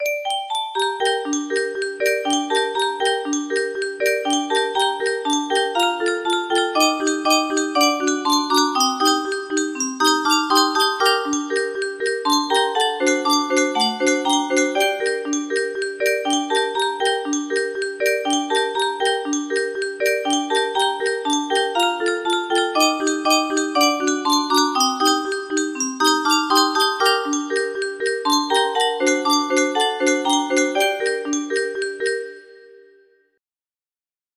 Bella Ciao music box melody
Full range 60